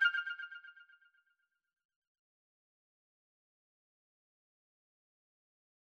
back_style_4_echo_002.wav